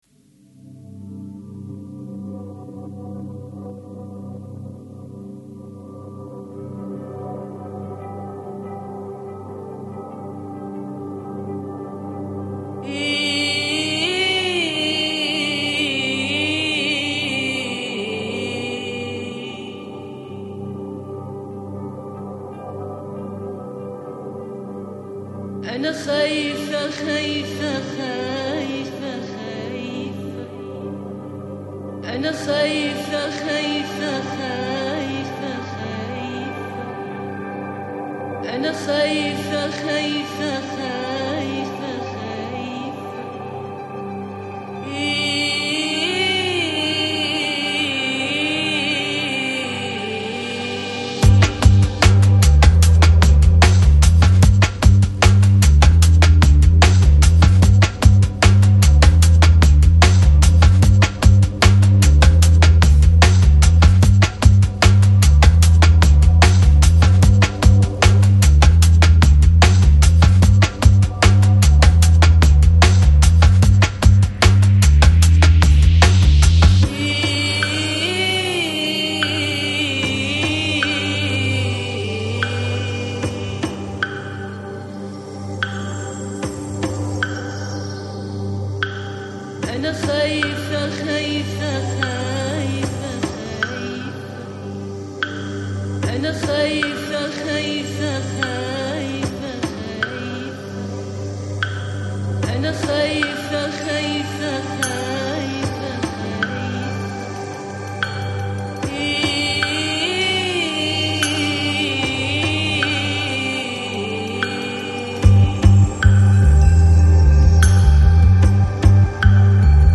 Жанр: Balearic, Downtempo